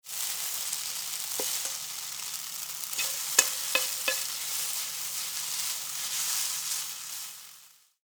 焼きそばを焼く2.mp3